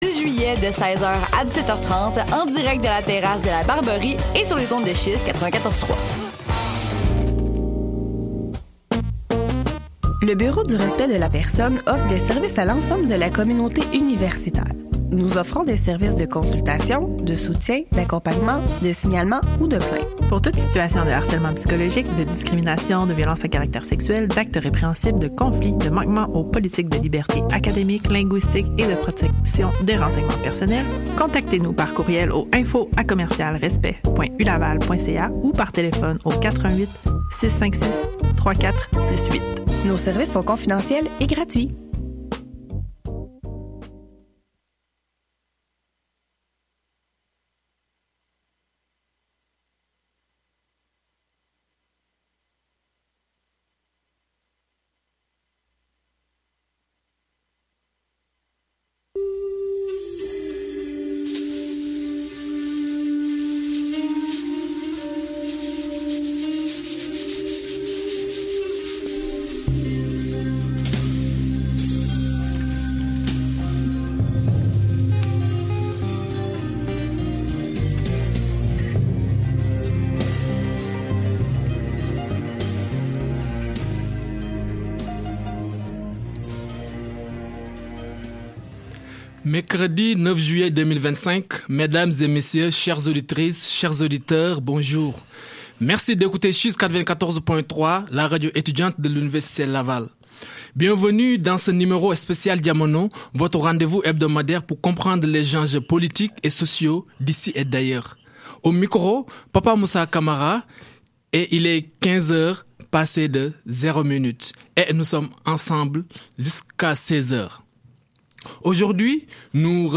En interview à l’université Laval sur les ondes de CHYZ 94,3 – Je suis une Amazone